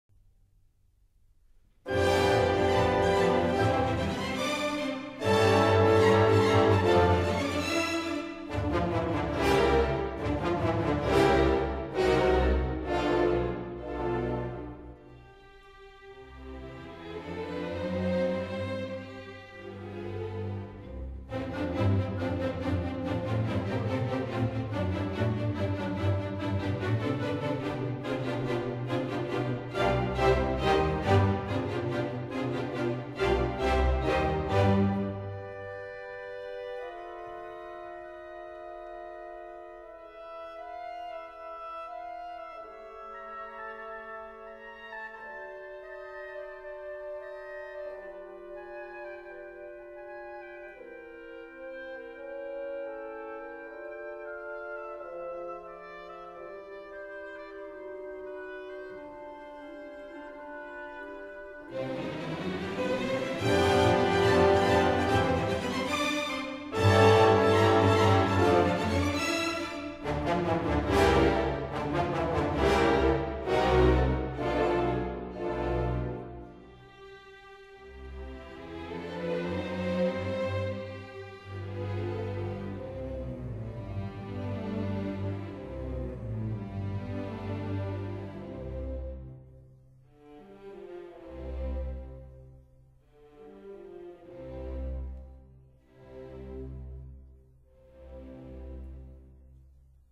Sorry, 论坛限制只许上传10兆一下，而且现在激动的网速巨慢，所以只能牺牲品质，为大家介绍一下音乐了。